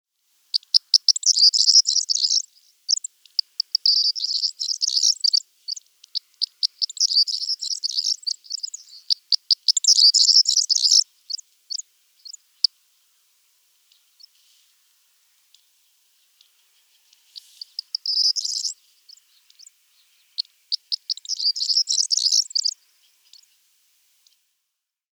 Red List Forty One – Corn Bunting
It’s not for want of trying to survive on the Corn Bunting’s part though – one male had 18 partners in a single season, and the males sit at the top of a tall weed or fence post and sing their heads off for hours. The song is described by the Crossley Guide as ‘like the jangle of keys being shaken, starts slow and accelerates’.